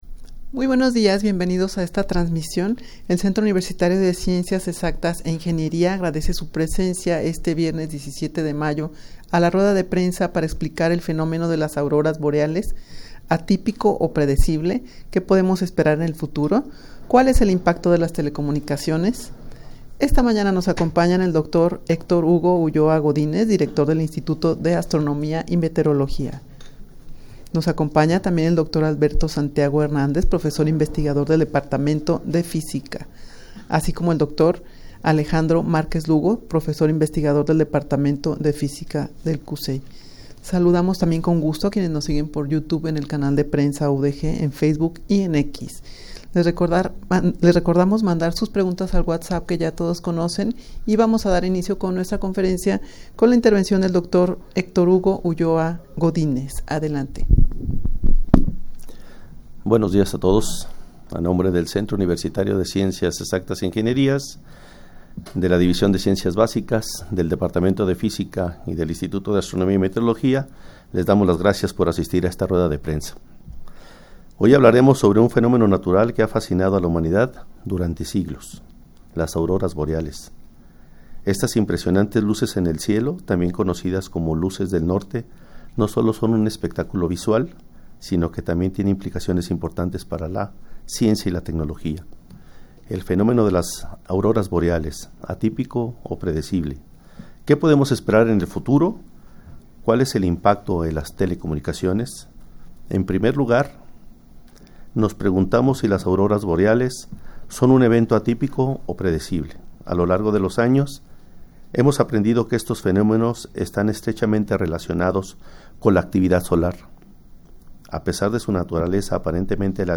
Audio de la Rueda de Prensa
rueda-de-prensa-para-explicar-el-fenomeno-de-las-auroras-boreales.mp3